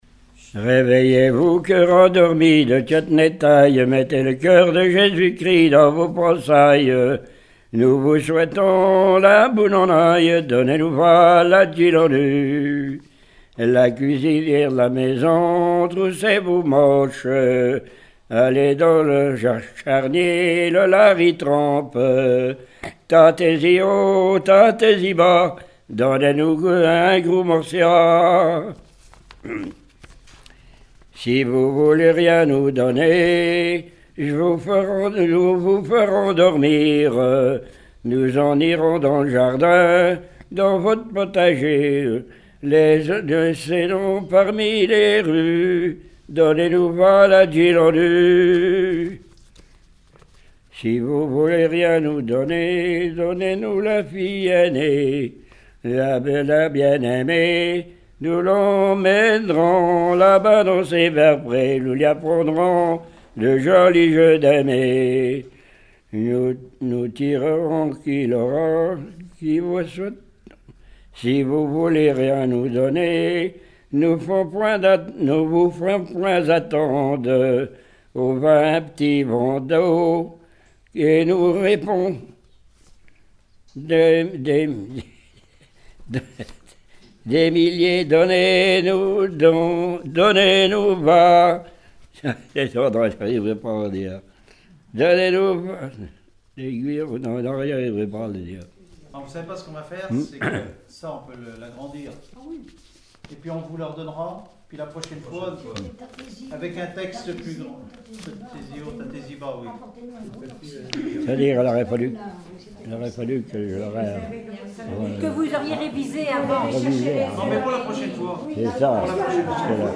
circonstance : quête calendaire
Genre strophique
collectif de chanteurs du canton
Pièce musicale inédite